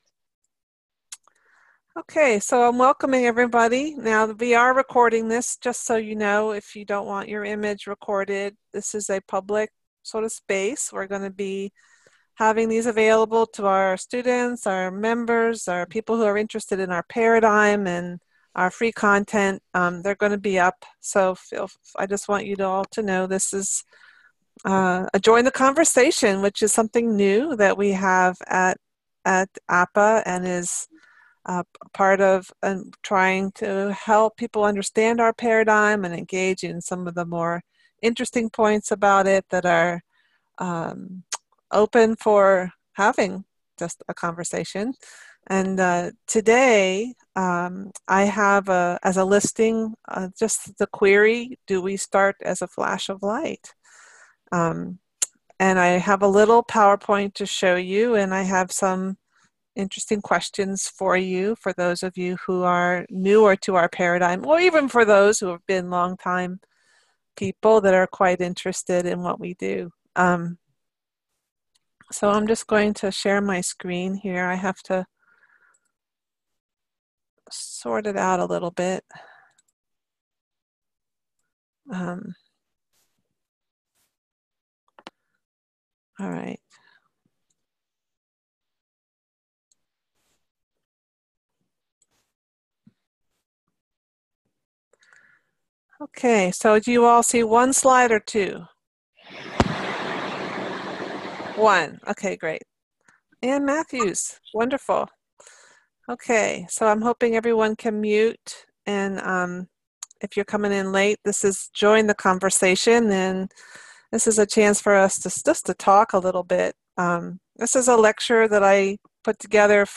Exploring the mystery about how we come into form takes a personal turn in this interactive forum.